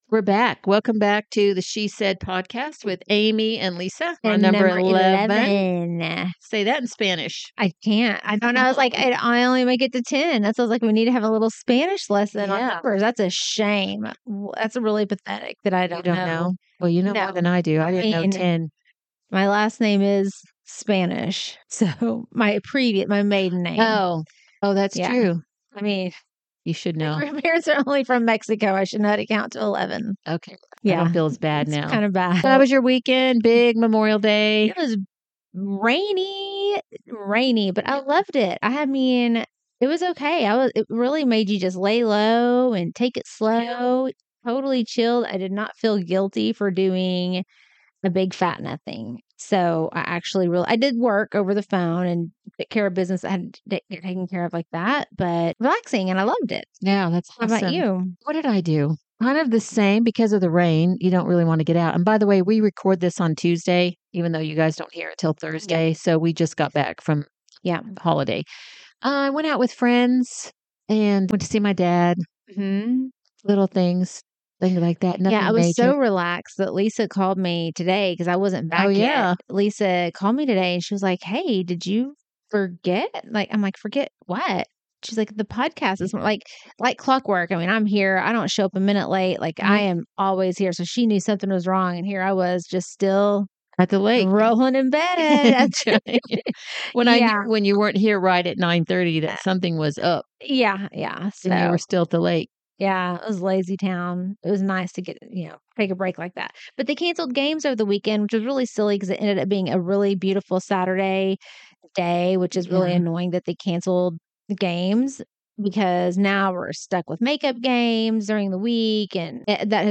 She Sed is what happens when two friends of over 25 years sit down to talk—really talk—about life. Join us as we explore the power of friendship, the things that lift us up, and the realities of health, happiness, and everything in between.
… continue reading 11 episodes # Society # Conversations # Podcasting Education # Self-Improvement # Sed